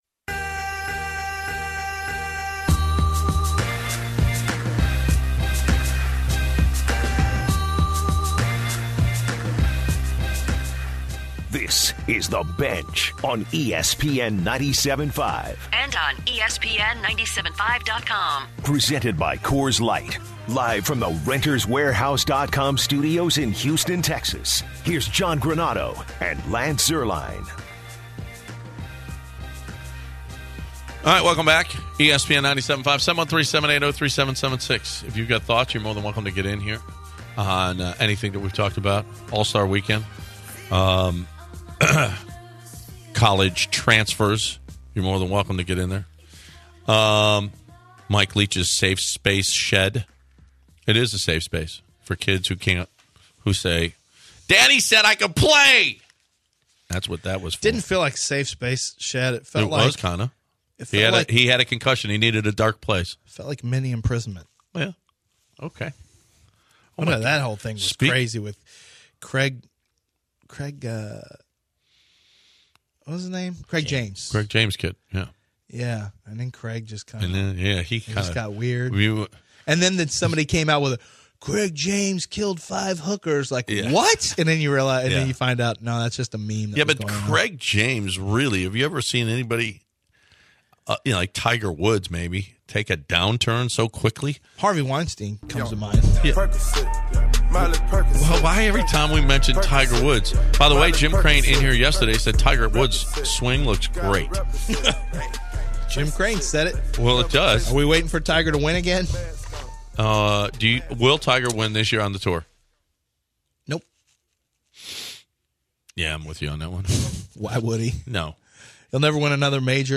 In their final hour of the week, the guys kick it off with some fun banter as they bounce around a variety of topics and stories. As the show rolls on, they talk NFL playoffs and discuss how many games they think the Astros will win this year.